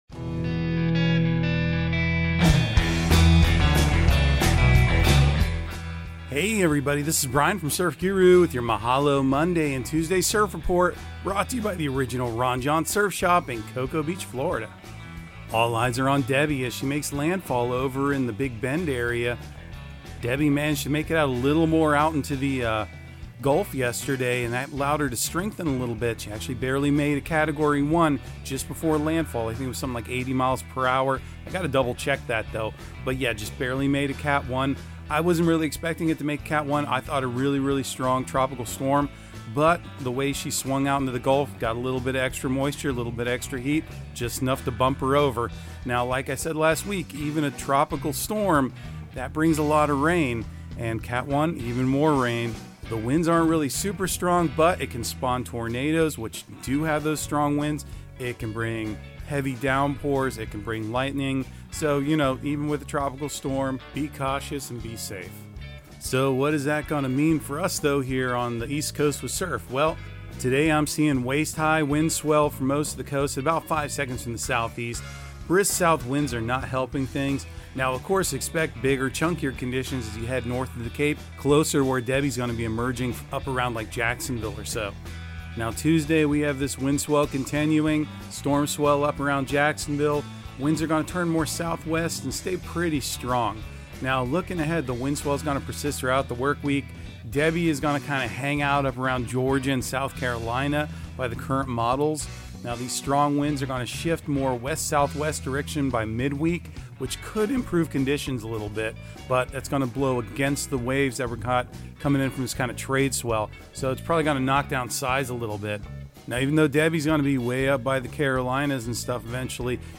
Surf Guru Surf Report and Forecast 08/05/2024 Audio surf report and surf forecast on August 05 for Central Florida and the Southeast. Your host will also enlighten you on current events in the surfing industry and talk about events and entertainment happenings in the local and regional area. Surf Guru is also sure to dig up some new music that will get your feet groovin'.